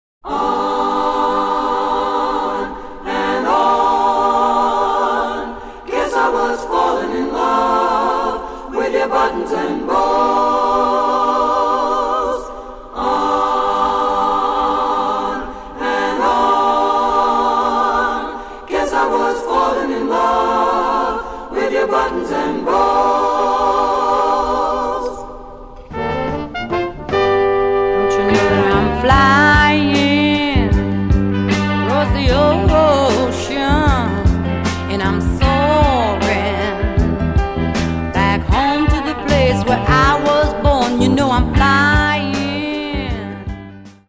FREE SOUL/RARE GROOVE